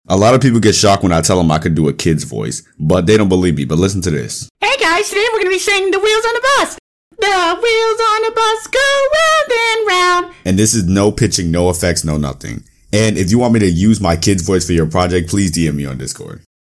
does my kid voice sound sound effects free download